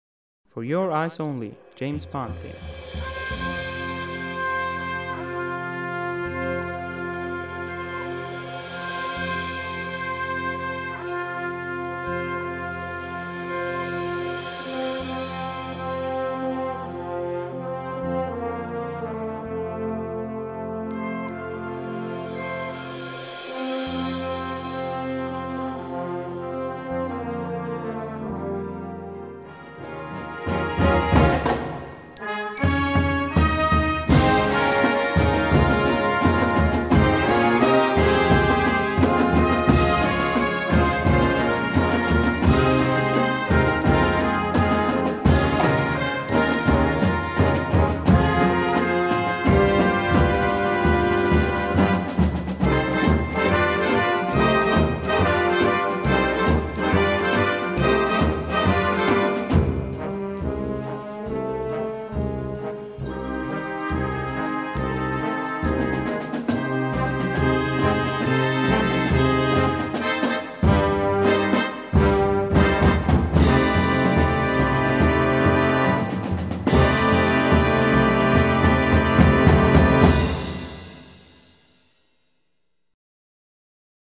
2:10 Minuten Besetzung: Blasorchester Tonprobe